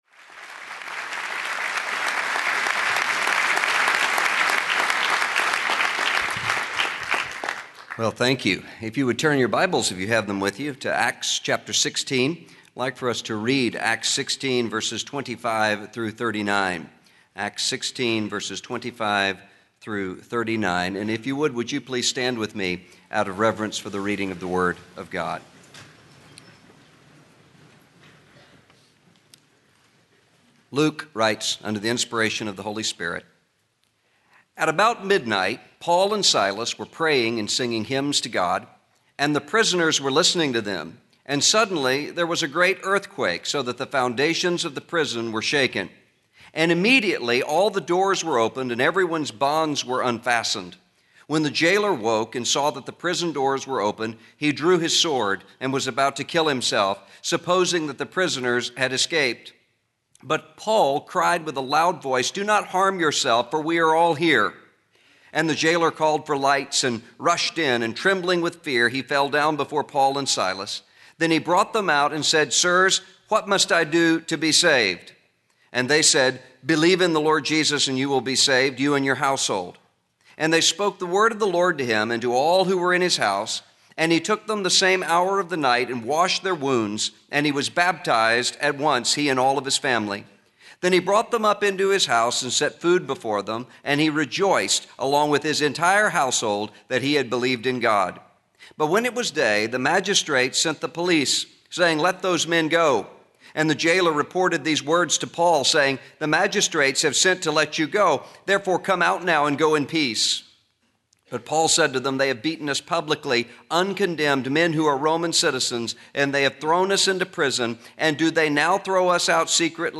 Presidential Inauguration Charge: Russell D. Moore